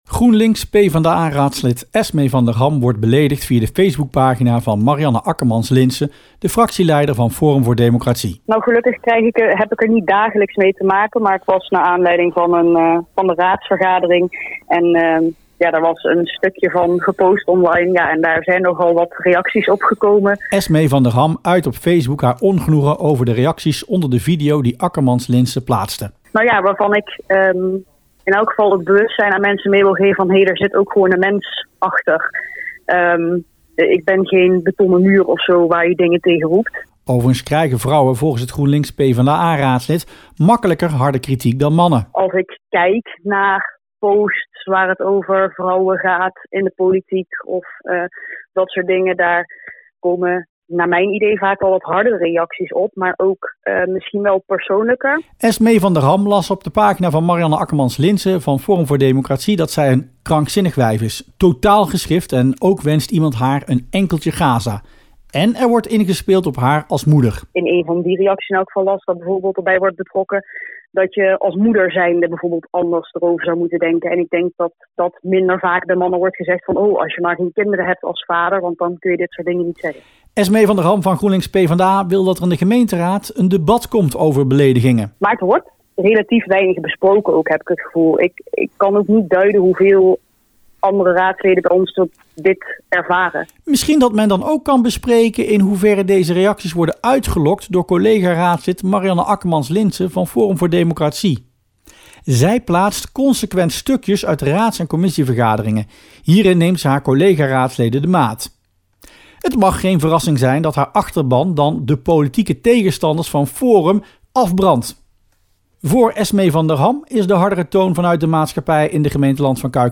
Radiobijdrage politiek verslaggever